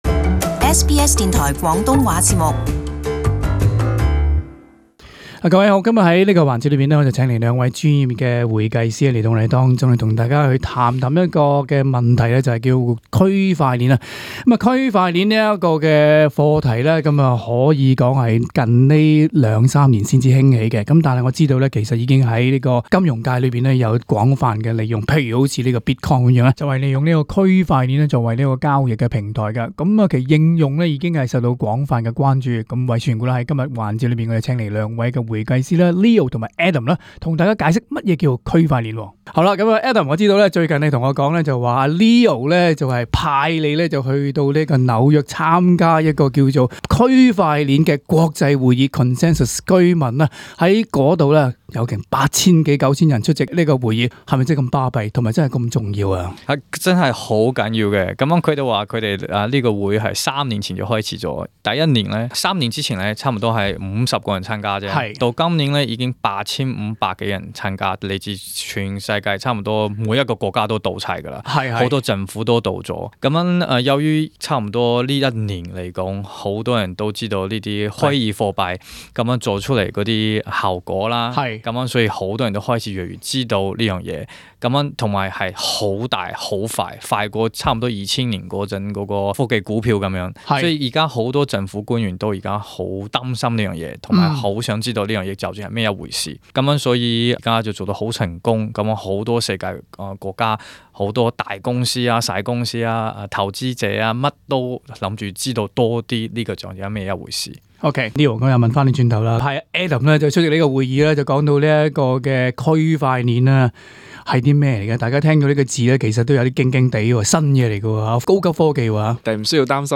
【社区专访】区块链如今扮演器重的生産模式